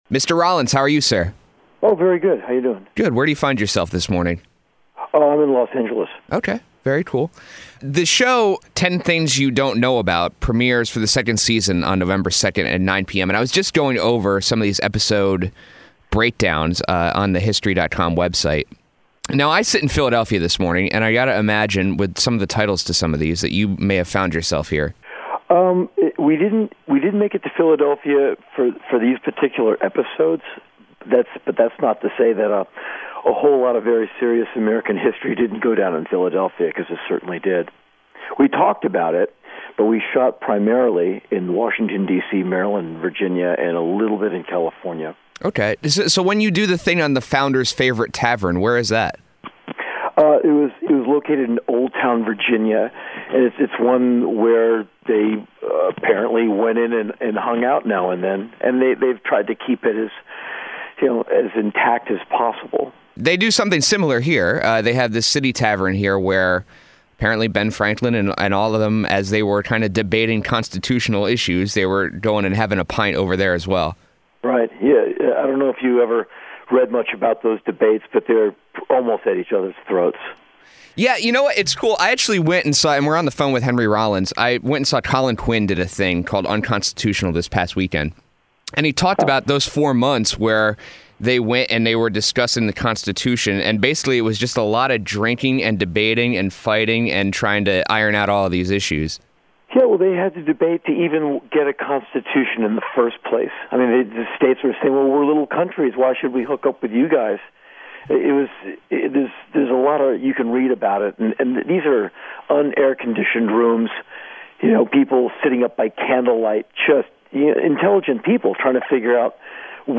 Interview: Henry Rollins On Lou Reed, History, And Being Red
henry-rollins-interview.mp3